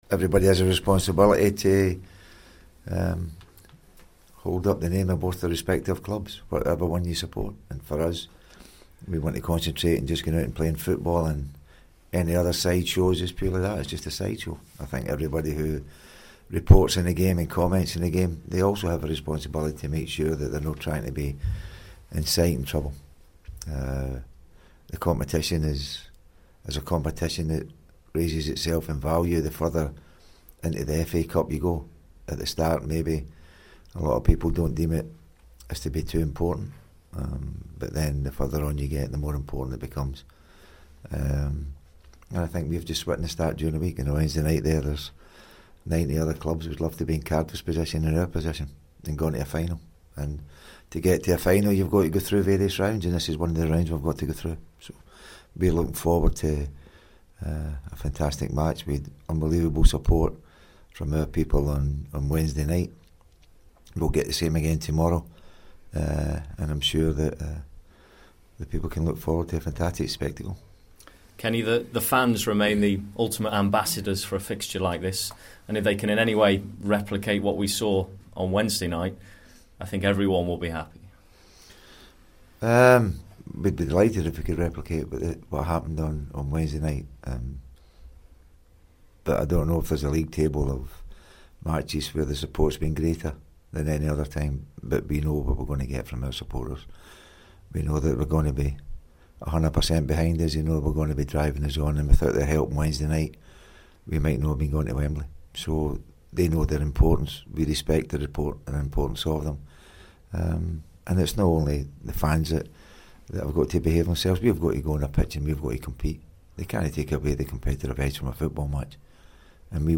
Kenny Dalglish pre-match press conference
Liverpool manager Kenny Dalglish talks to Radio City ahead of Saturday's FA Cup tie with Manchester United.